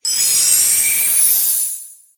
Cri de Beldeneige dans Pokémon HOME.